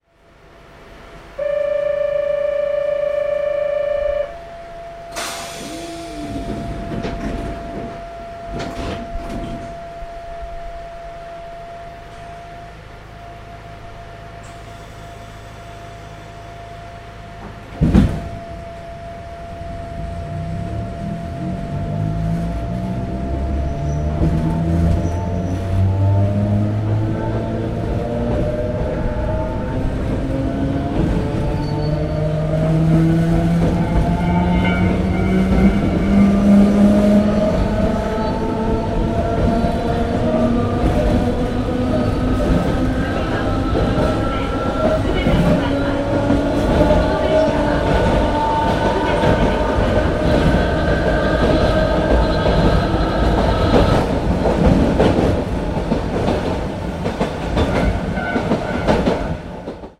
内部線 走行音(60秒・1.14MB)   森ヶ山(四日市市) 周辺